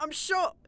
Becca Im hit.wav